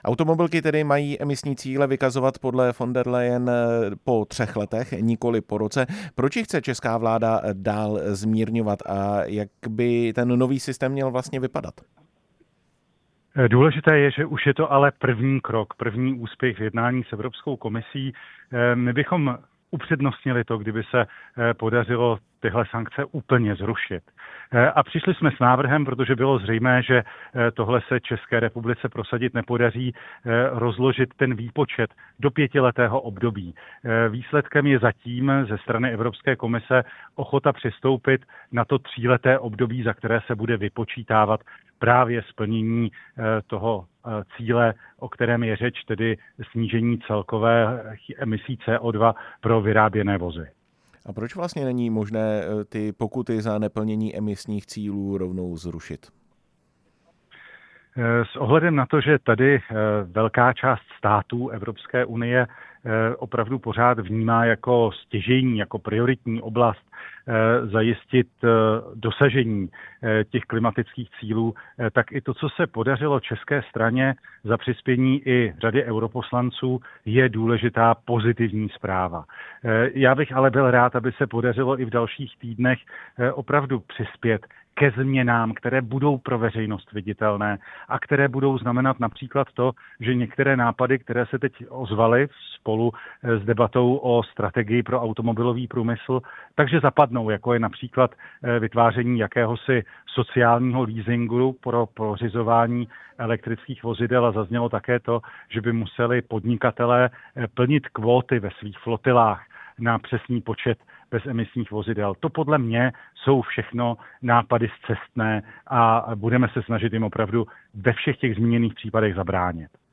Česká vláda bude požadovat další zmírnění. Ve vysílání Radia Prostor jsme se na podrobnosti ptali ministra dopravy Martina Kupky.
Rozhovor s ministrem dopravy Martinem Kupkou